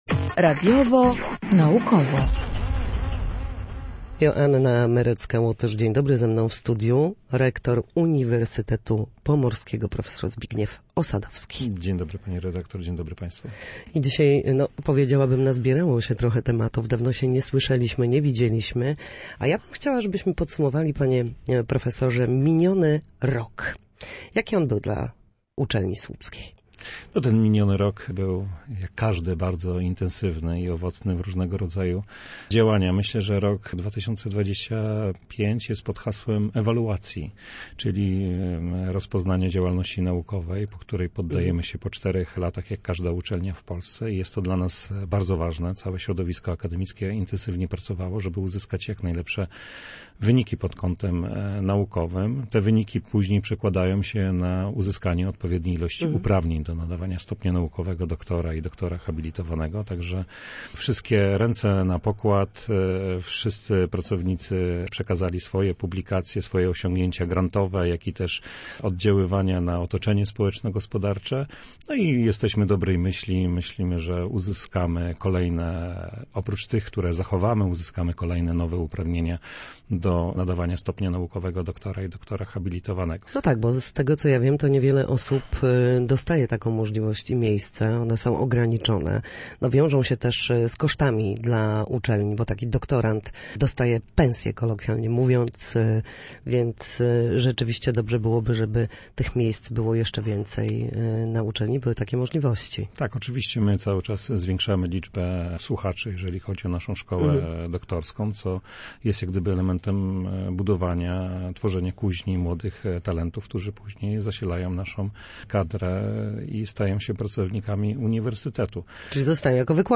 Uniwersytet Pomorski w Słupsku podsumowuje rok intensywnych inwestycji i zapowiada kolejne działania rozwojowe. Gościem Studia Słupsk jest dr hab. inż.